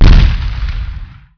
explode_minor.wav